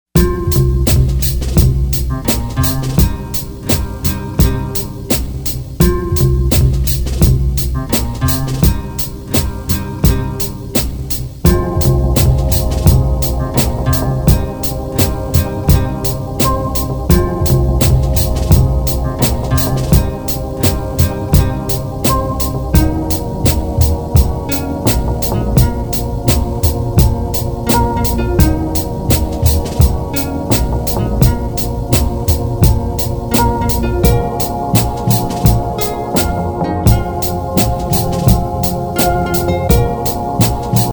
• Качество: 256, Stereo
красивые
без слов
инструментальные
Alternative Soul